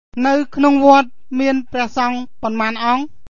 nuh-oo kh*'nong vo-at mee-uhn pray-uhH sawng ponmân awng